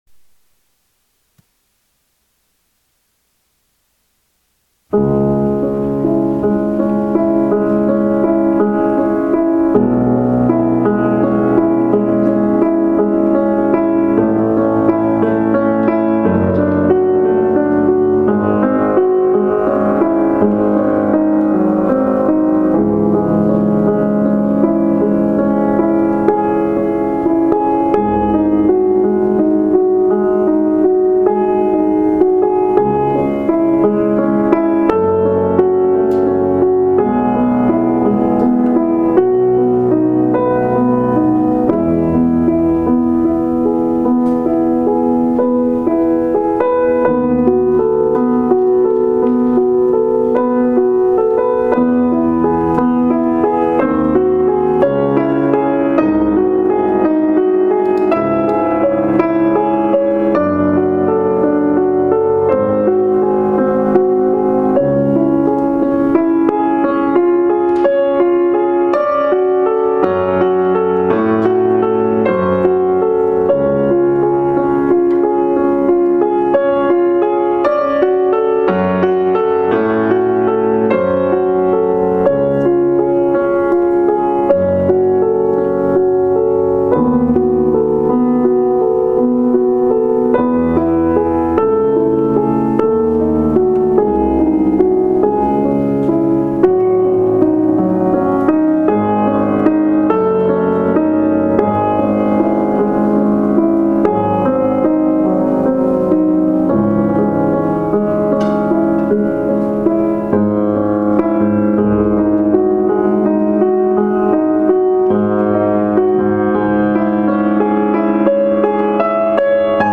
ﾋﾟｱﾉｿﾅﾀ第14番
piano